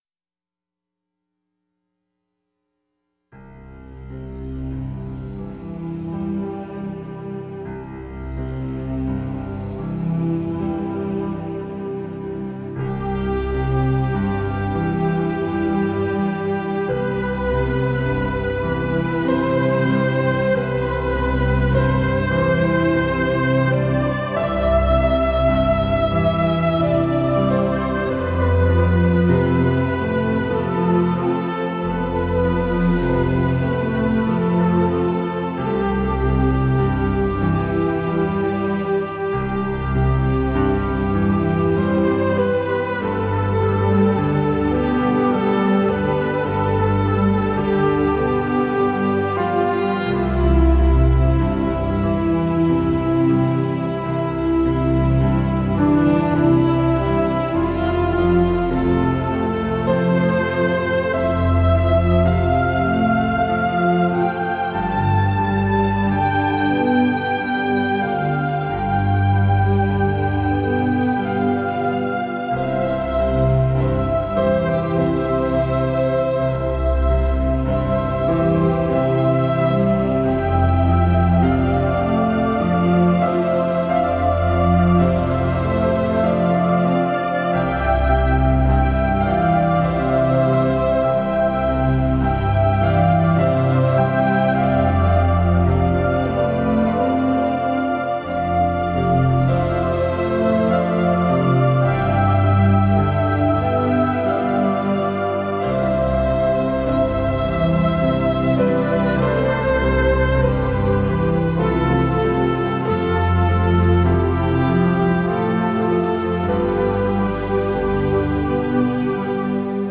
soothing meditative piano music
Perfect for relaxation, meditation, and stress relief.
piano